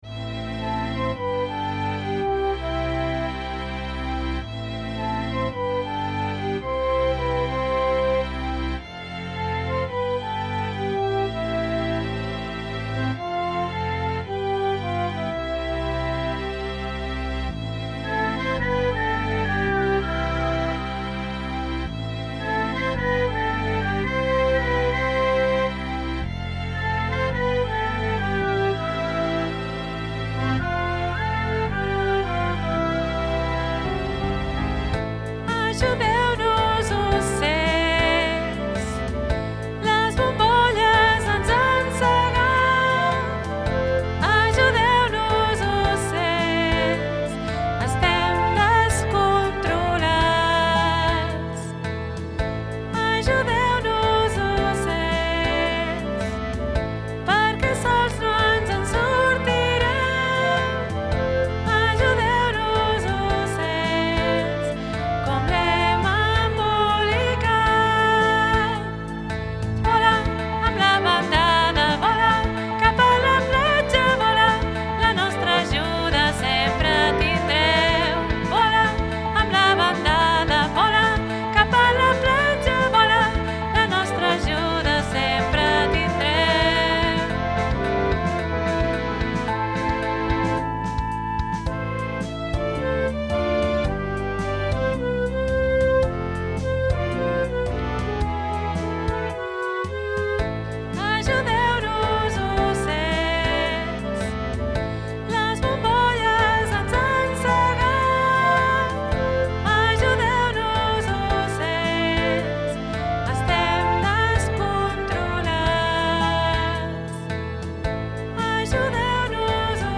Demo-vola-amb-veu.mp3